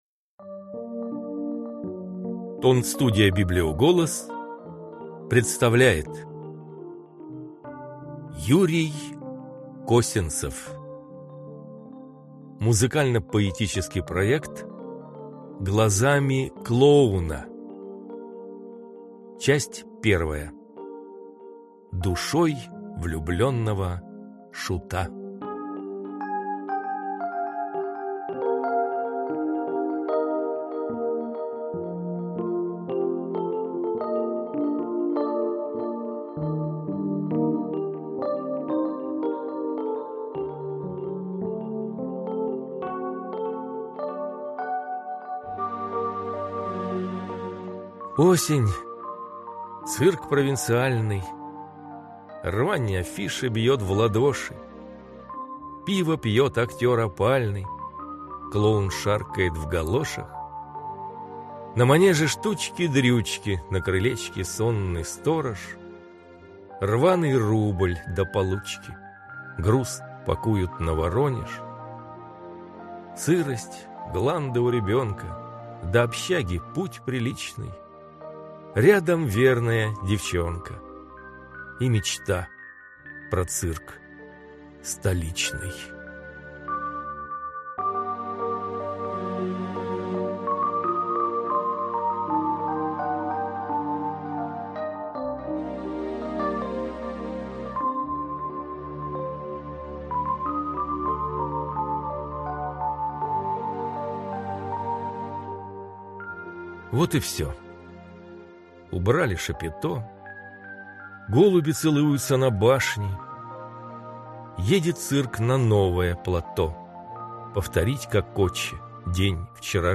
Аудиокнига Глазами клоуна | Библиотека аудиокниг